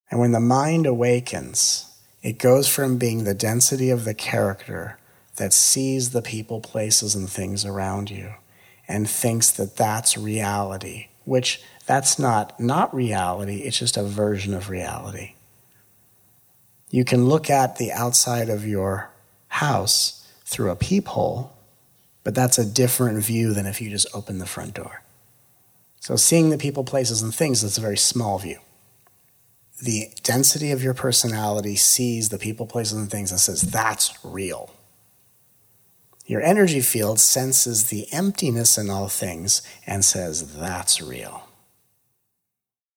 7.The Three Levels of Awakening - Encinitas Immersion (1:42:07)
Encinitas 2017 sample_quotes - The Levels of Awakening - quote5.mp3